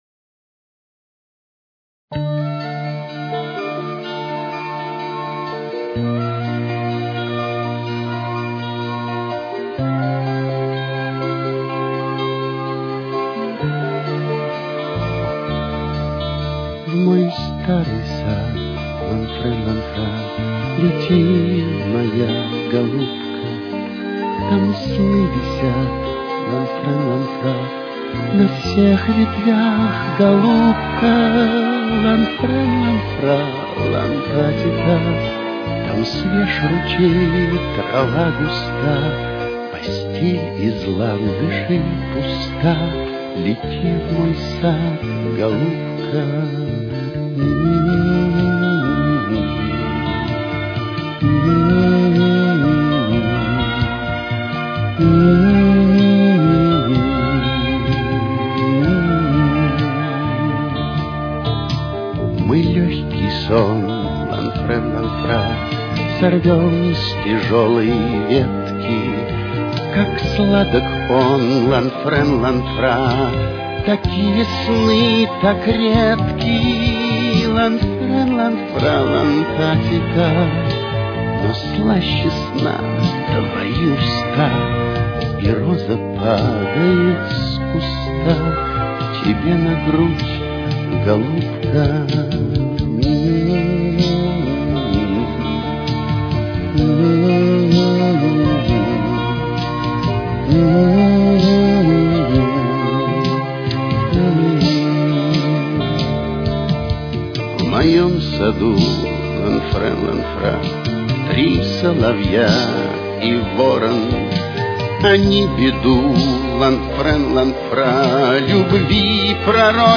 с очень низким качеством (16 – 32 кБит/с)
Ре минор. Темп: 65.